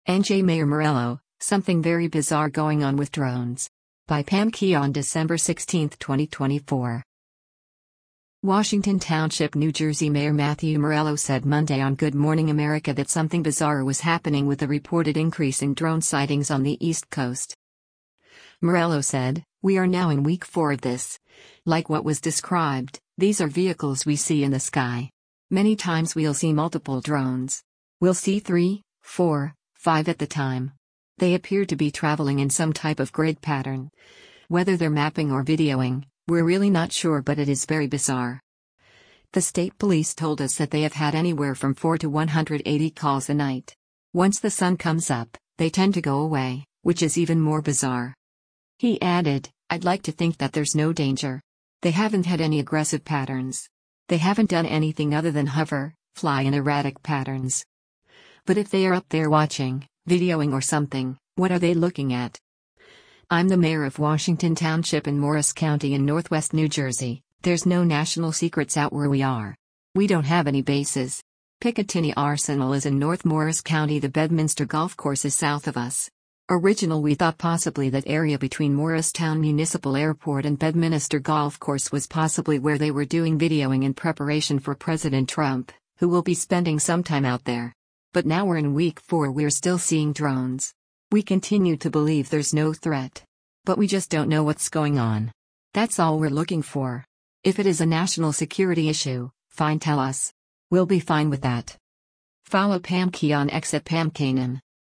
Washington Township, NJ Mayor Matthew Murello said Monday on “Good Morning America” that something “bizarre” was happening with the reported increase in drone sightings on the East Coast.